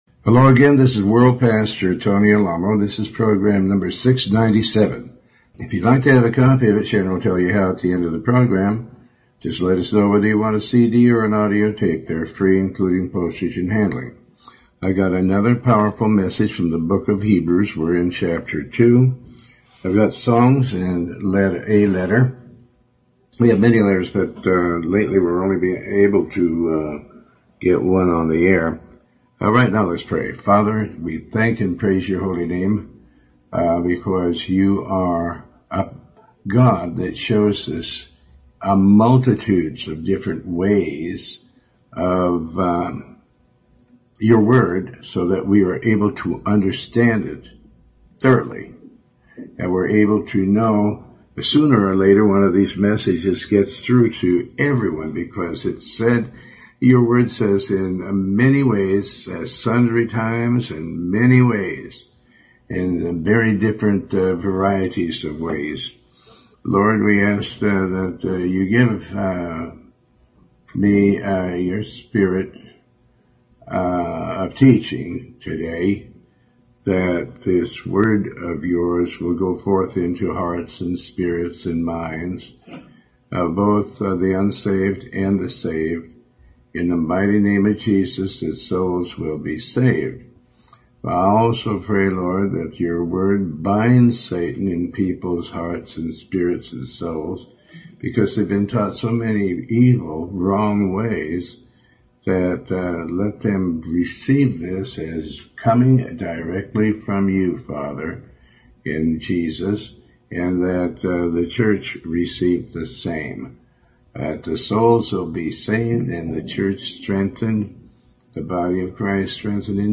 Talk Show Episode, Audio Podcast, Tony Alamo and You can not send my spirit in to Hell.